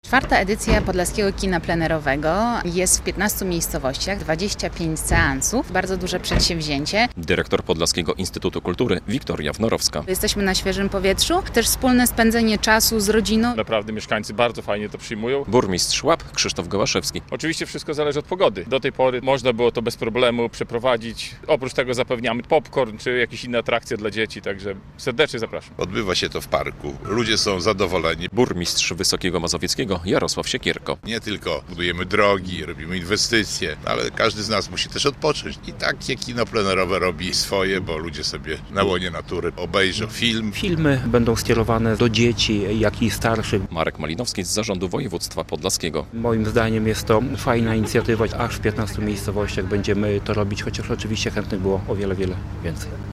Zaczyna działać Podlaskie Kino Plenerowe - relacja